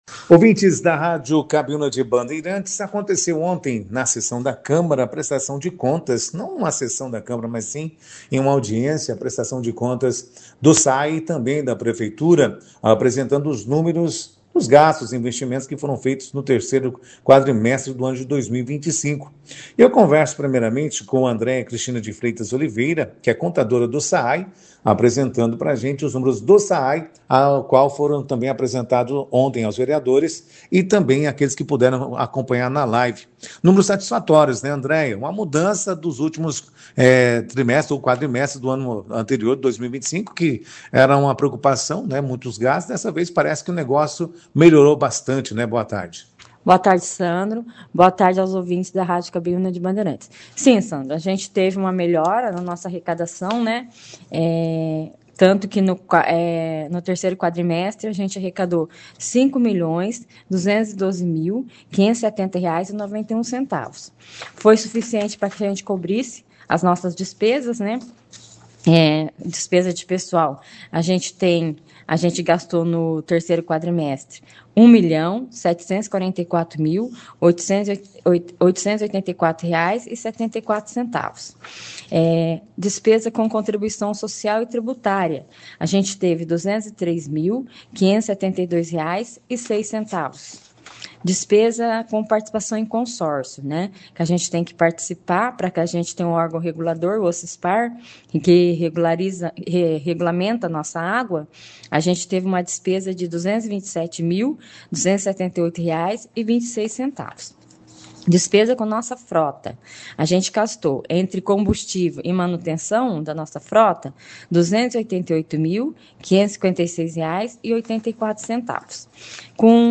As duas prestações de contas foram destaque na 2ª edição do Jornal Operação Cidade desta quinta-feira (26), com a participação de representantes da Prefeitura e do SAAE.